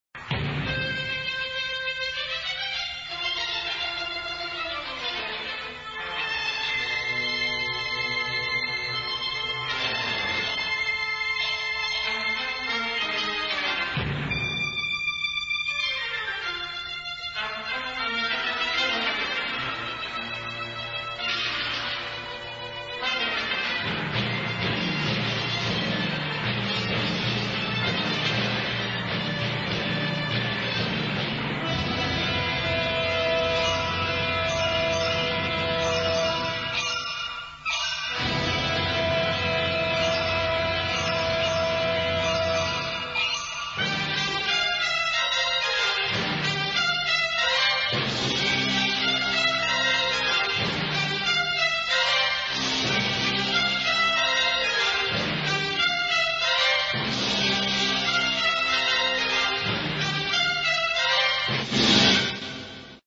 Ein brillantes, mitreißendes Orchesterwerk.